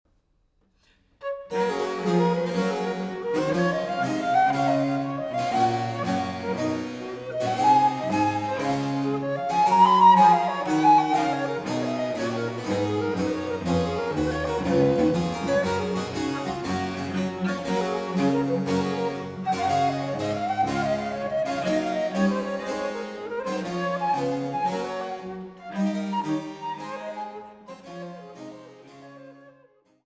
Traversflöte